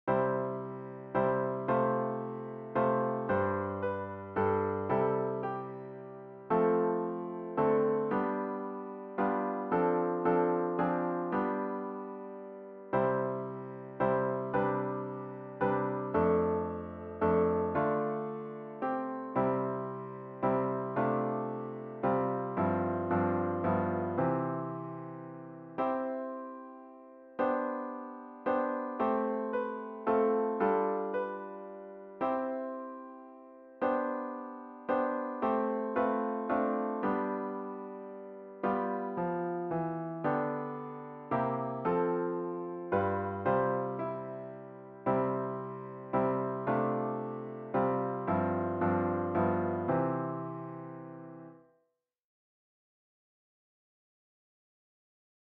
New melody with interesting harmony.